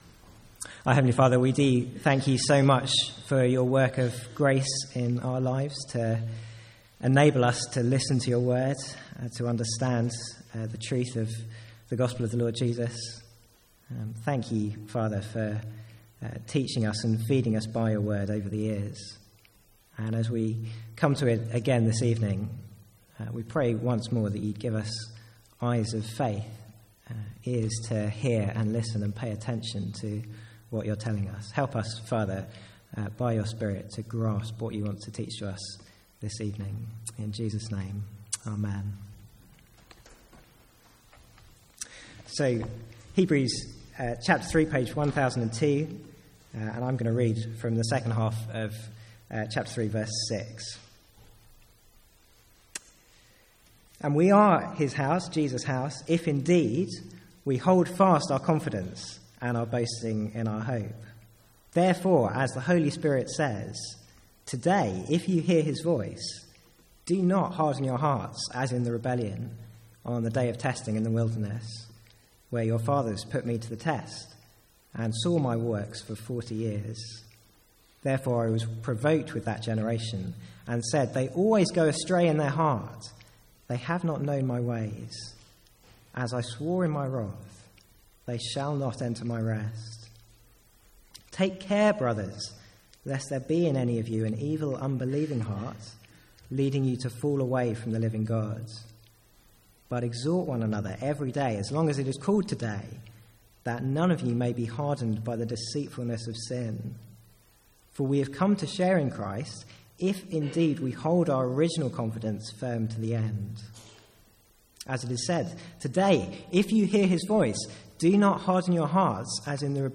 From the Sunday evening series in Hebrews.